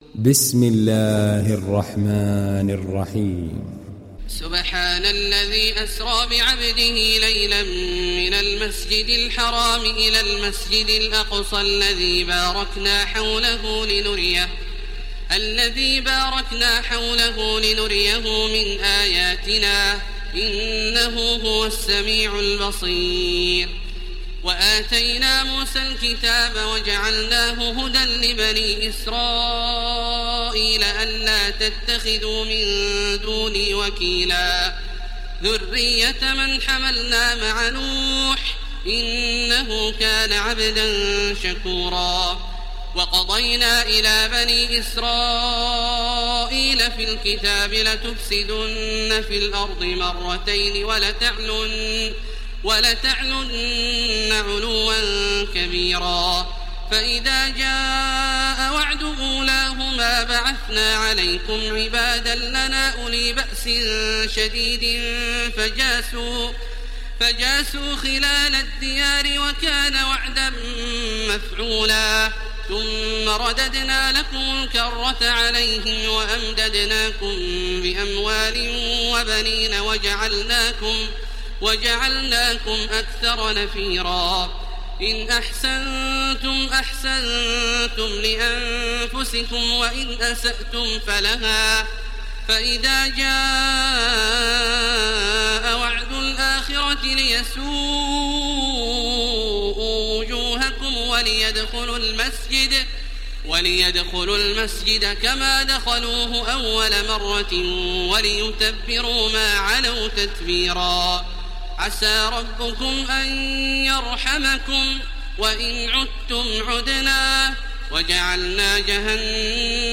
دانلود سوره الإسراء mp3 تراويح الحرم المكي 1430 روایت حفص از عاصم, قرآن را دانلود کنید و گوش کن mp3 ، لینک مستقیم کامل
دانلود سوره الإسراء تراويح الحرم المكي 1430